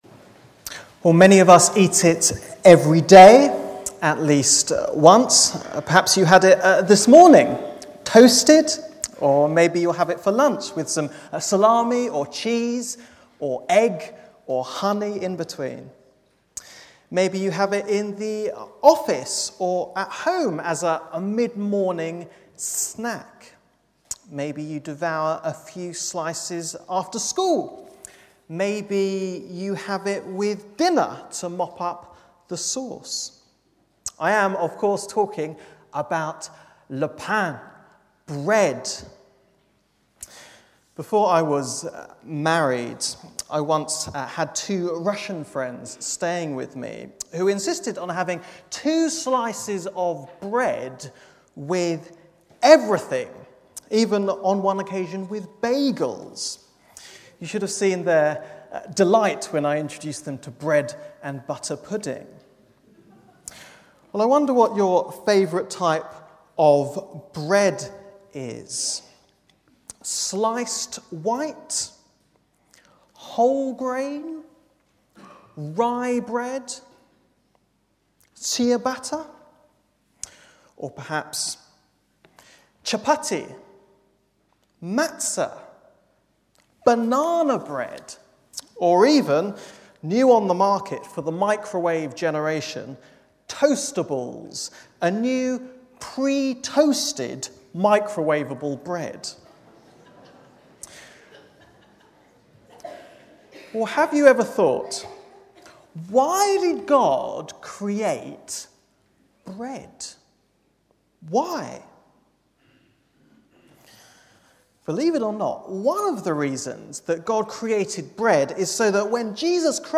Bible Text: John 6:1-15 | Preacher